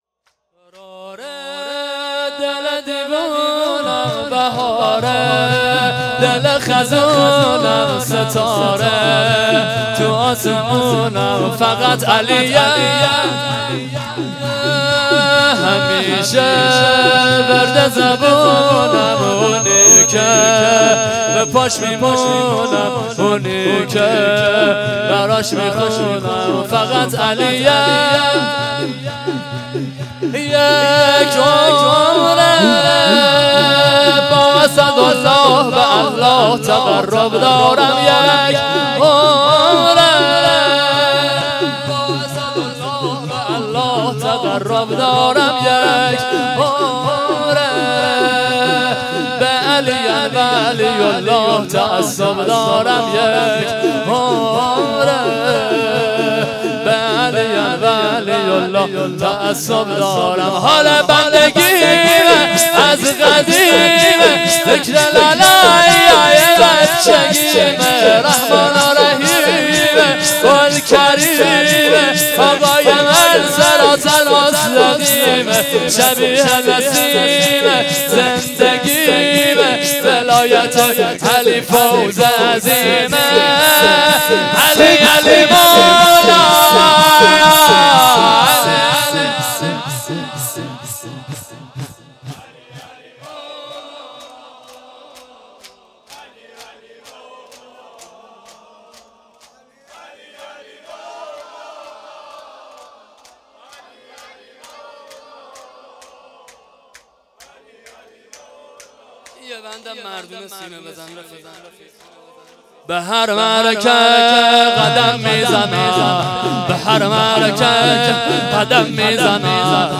سینه زنی
sine-zani3.mp3